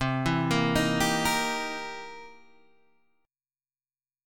C7#9 chord {8 7 8 8 8 6} chord